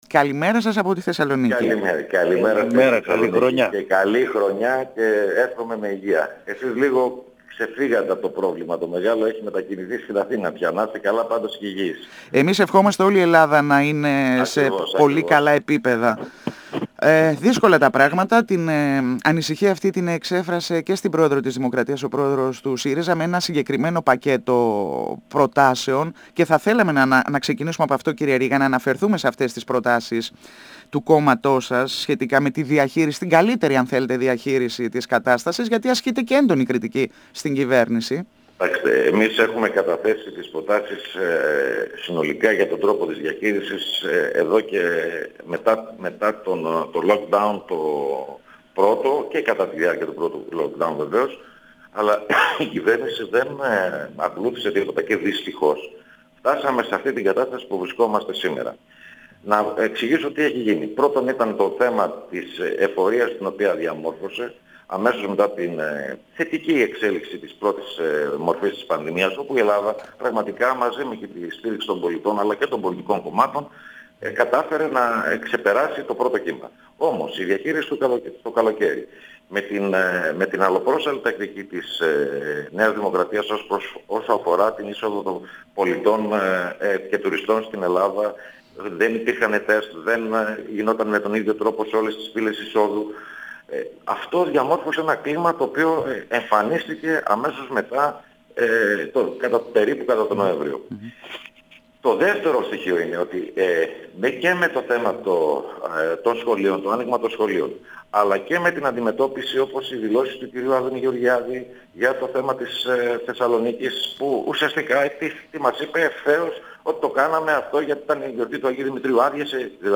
Ο τέως γραμματέας του ΣΥΡΙΖΑ Πάνος Ρήγας στον 102 fm της ΕΡΤ3
Συνεντεύξεις